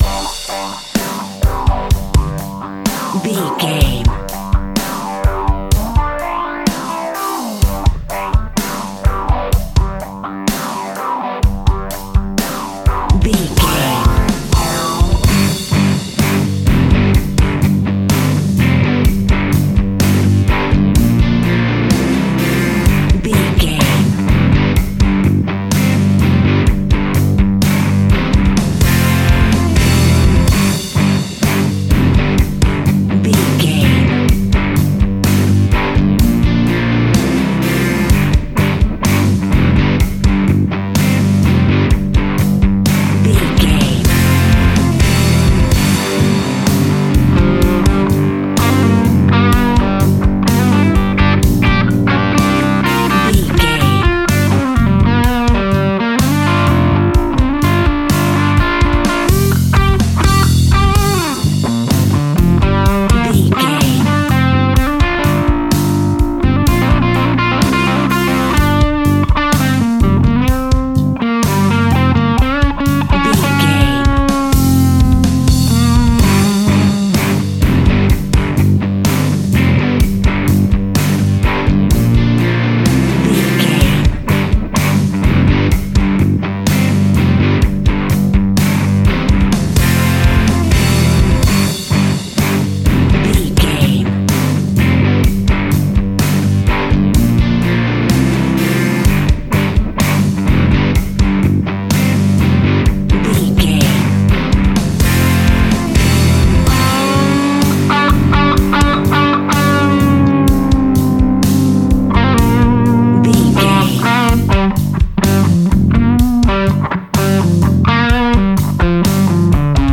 Ionian/Major
energetic
driving
happy
bright
electric guitar
bass guitar
drums
hard rock
blues rock
distortion
heavy drums
distorted guitars
hammond organ